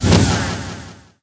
ninja_invis_end_01.ogg